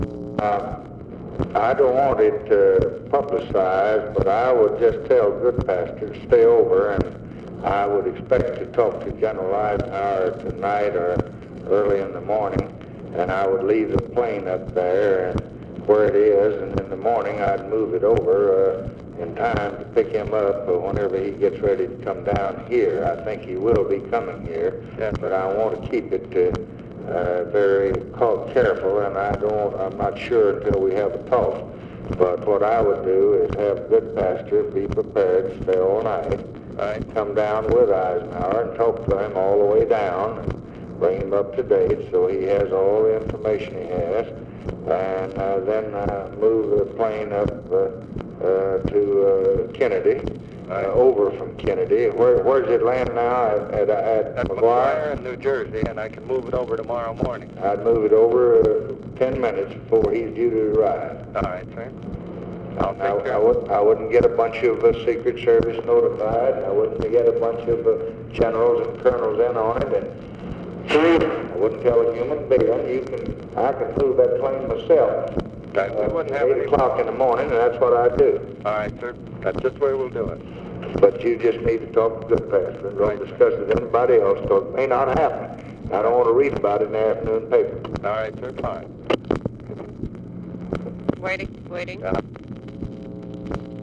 Conversation with C. V. CLIFTON, February 16, 1965
Secret White House Tapes